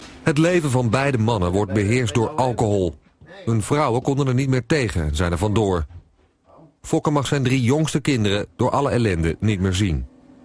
Presentator
Category: Television   Right: Personal